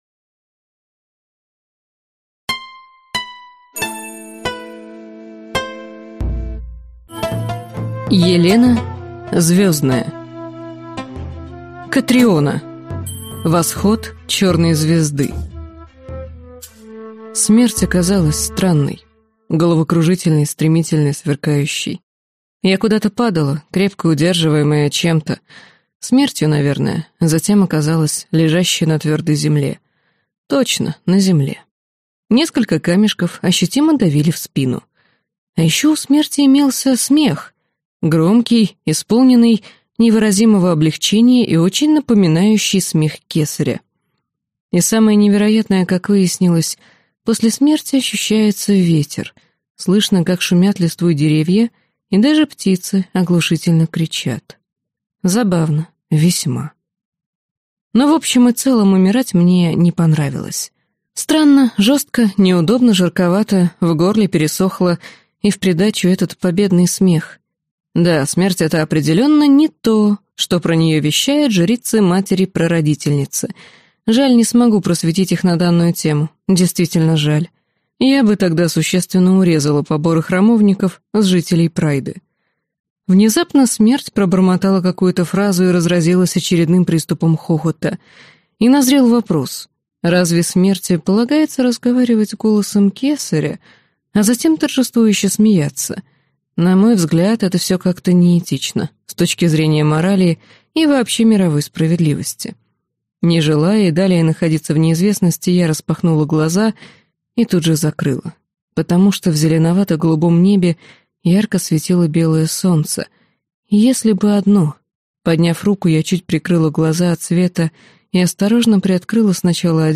Аудиокнига Восход Черной звезды - купить, скачать и слушать онлайн | КнигоПоиск